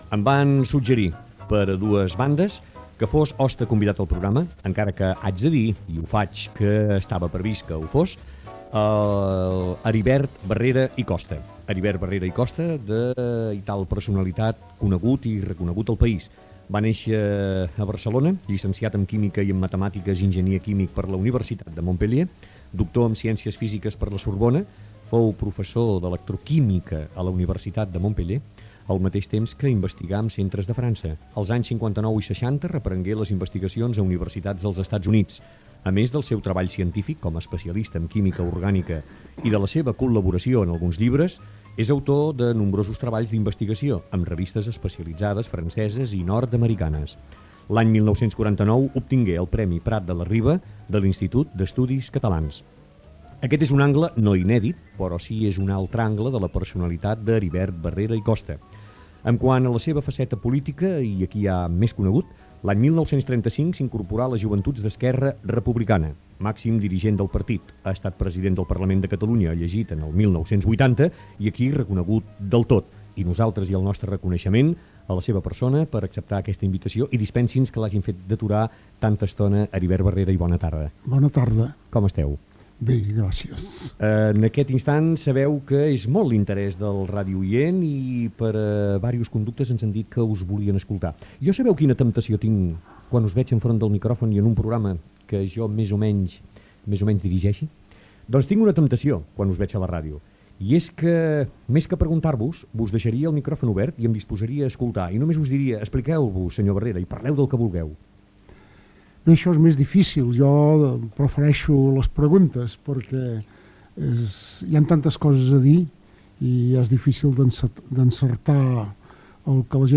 Perfil biogràfic i entrevista al polític Heribert Barrera d'Esquerra Republicana de Catalunya